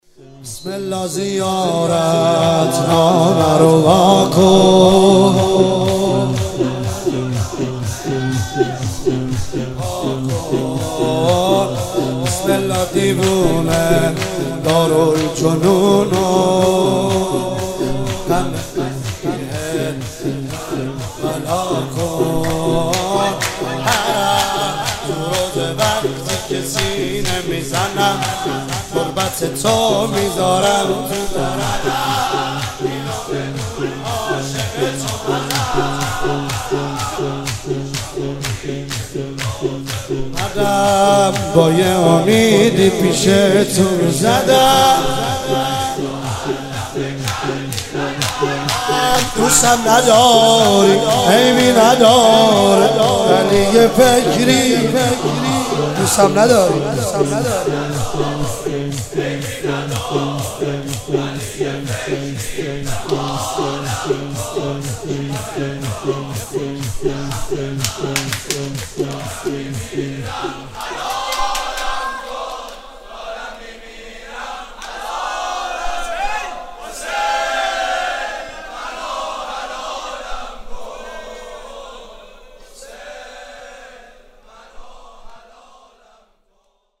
جلسه هفتگی هیئت حسین جان گرگان
شور – بسم الله زیارتنامه رو واکن mp3 ۱/۴۰